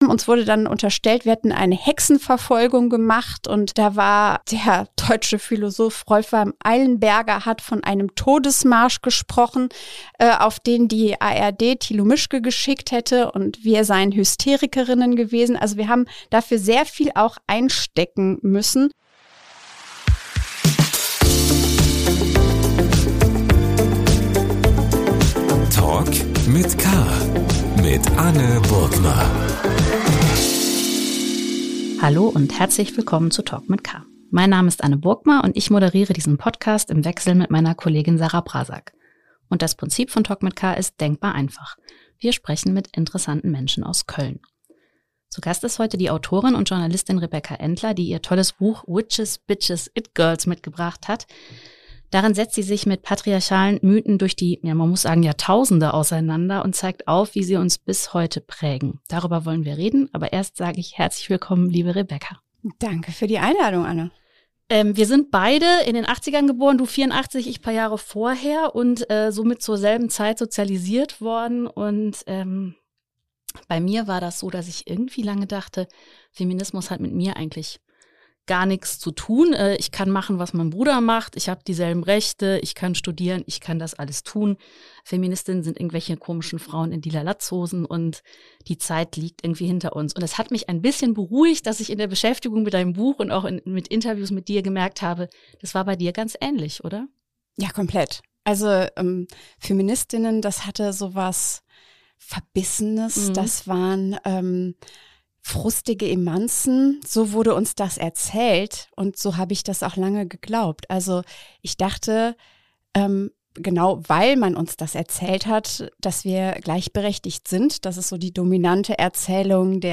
Die Autorin und Journalistin im Gespräch. ~ Talk mit K - der Talk-Podcast des Kölner Stadt-Anzeiger Podcast